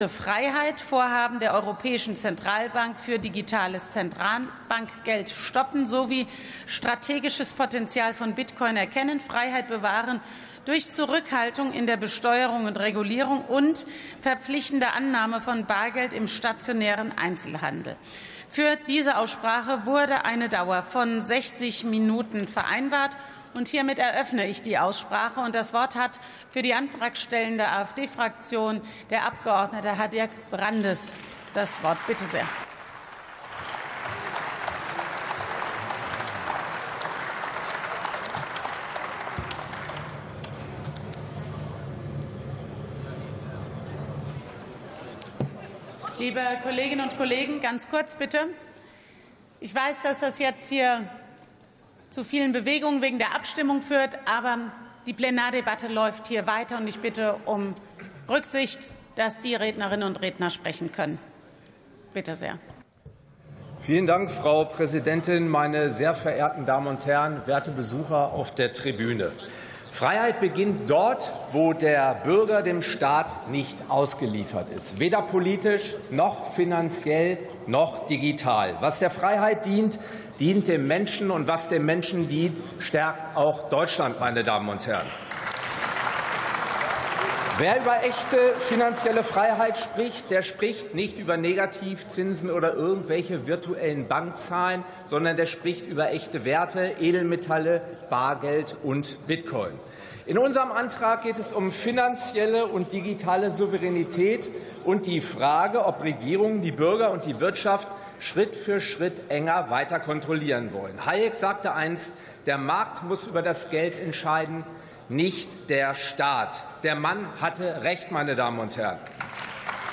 48. Sitzung vom 05.12.2025. TOP 28: Bargeld und Bitcoin ~ Plenarsitzungen - Audio Podcasts Podcast